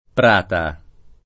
pr#ta] top. — talvolta inteso come sing. f. (per prato): Prata Sannita [